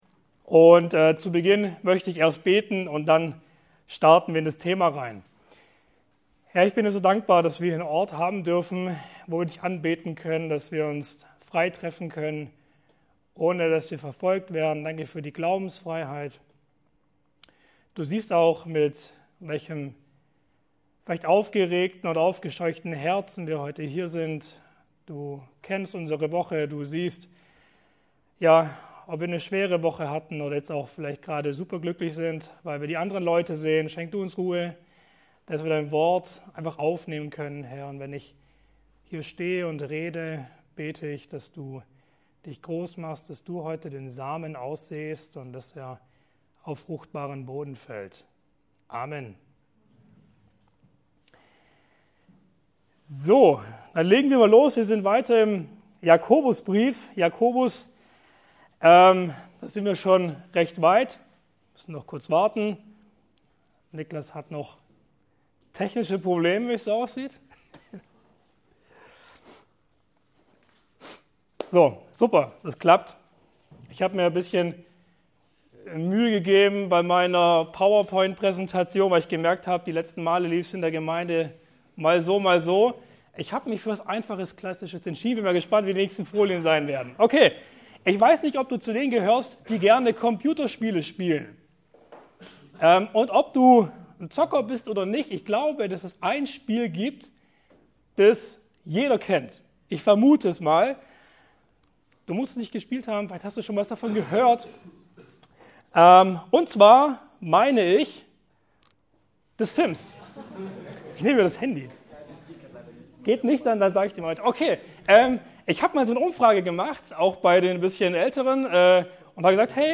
Passage: Jakobus 4,13-17 Dienstart: Jugendstunden